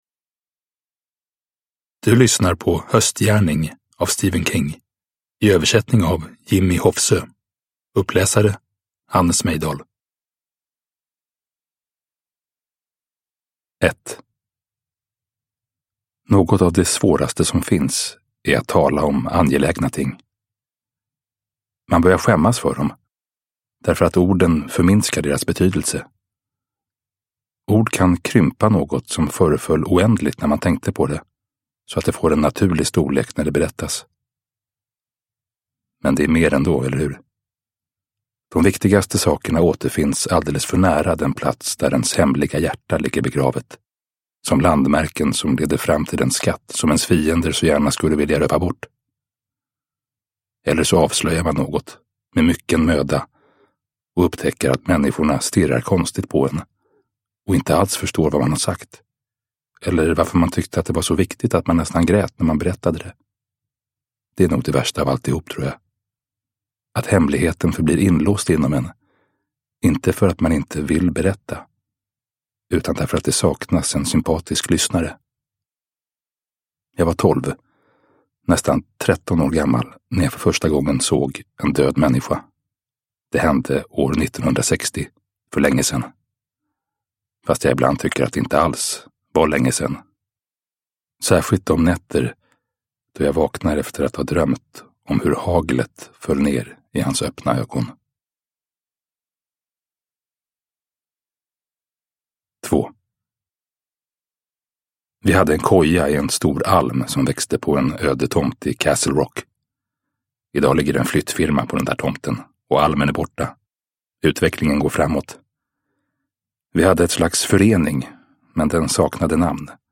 Höstgärning (Stand by me). En av berättelserna ur novellsamlingen Årstider – Ljudbok – Laddas ner